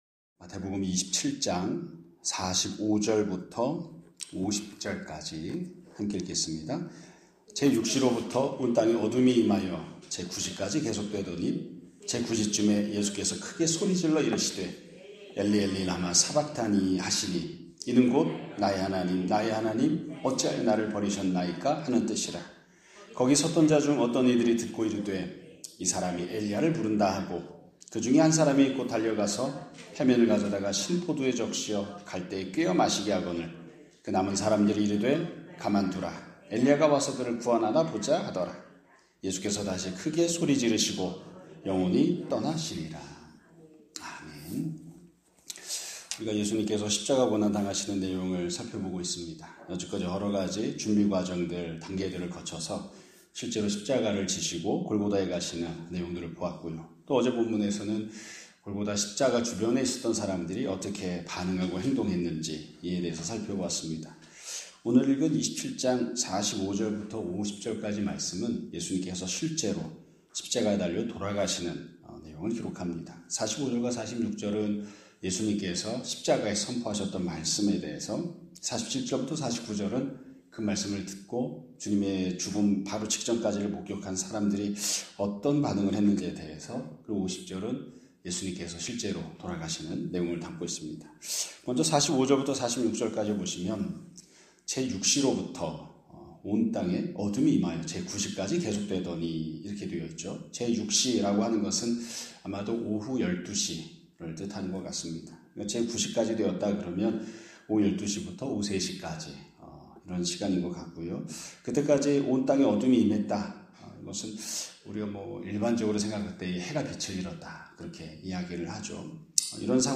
2026년 4월 22일 (수요일) <아침예배> 설교입니다.